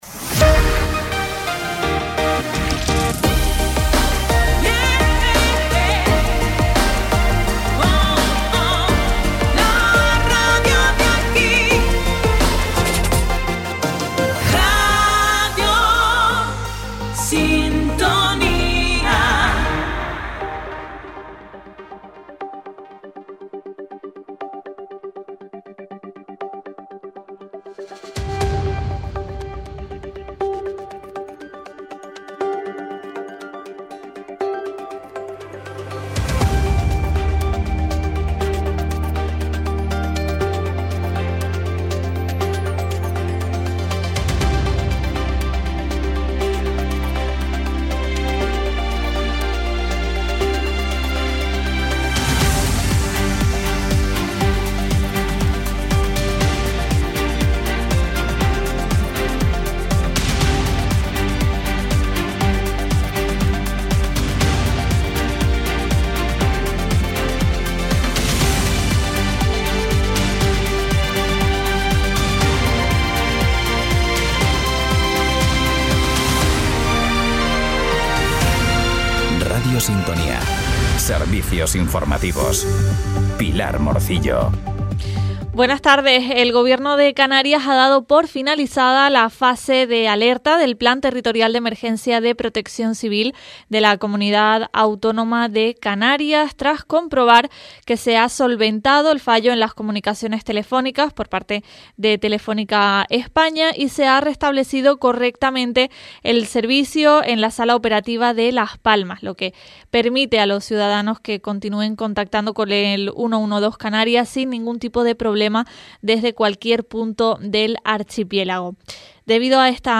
Informativos en Radio Sintonía - 21.05.25 - Radio Sintonía